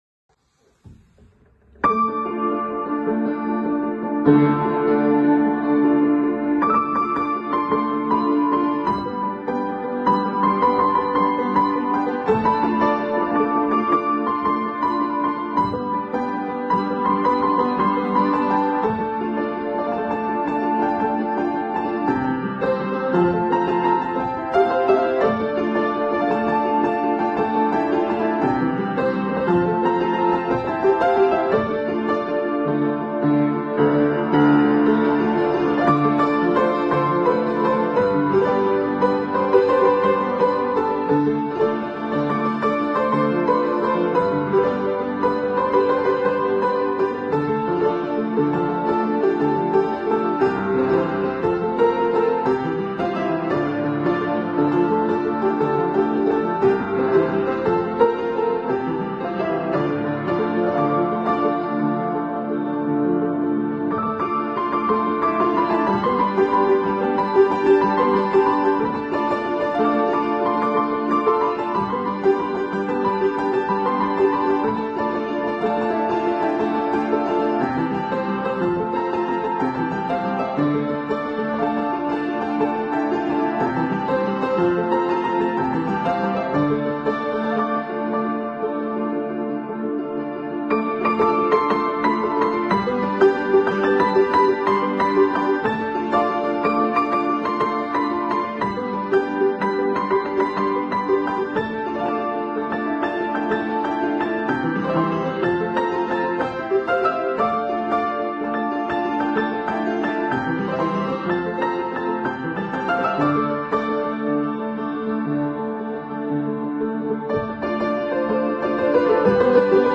на фортепиано